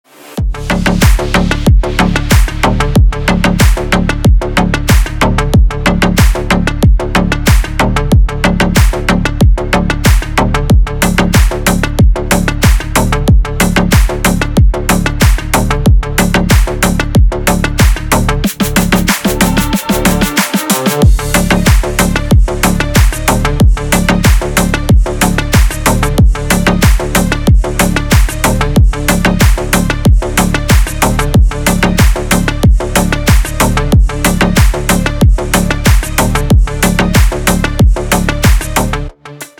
Клубные рингтоны